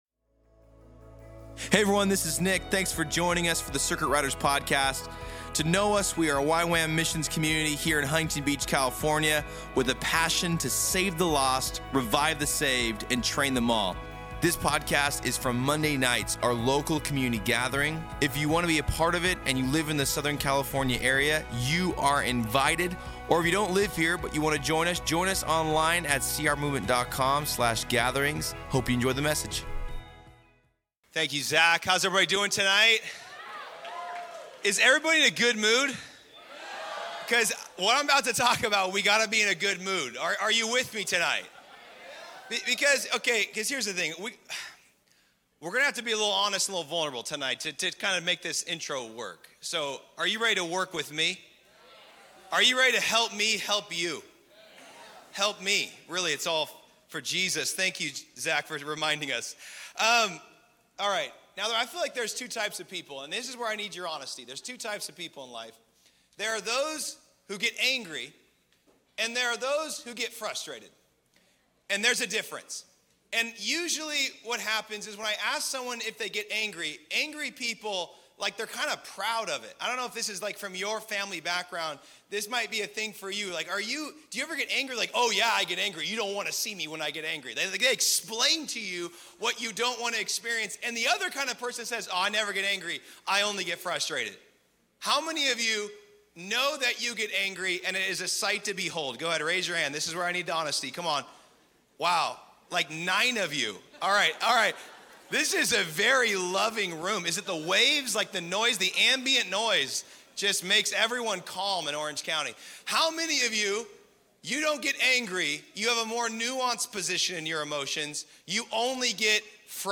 He ends his message by praying for everyone to receive an outpouring of God’s mercy in specific areas of their lives.